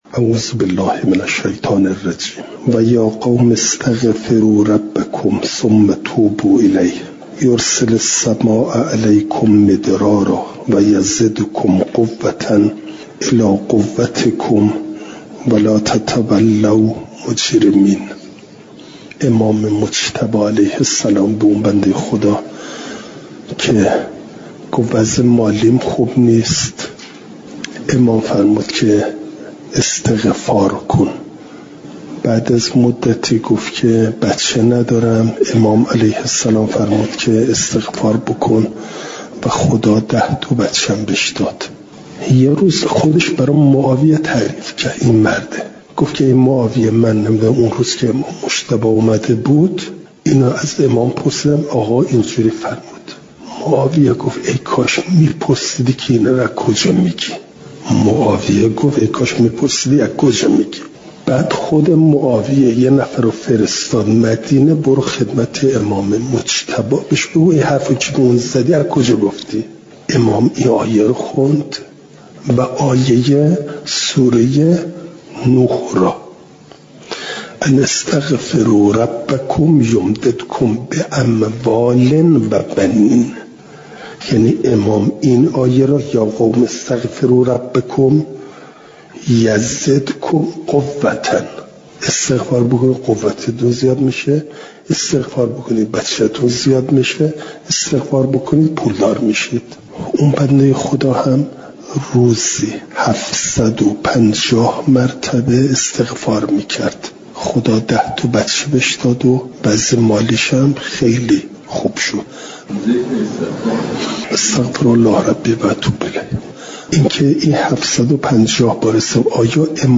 سخن کوتاه
شنبه ۳ آذرماه ۱۴۰۳، دارالقرآن علامه طباطبایی(ره)، برگرفته از جلسات ۷۴۳ و ۷۴۴ تفسیر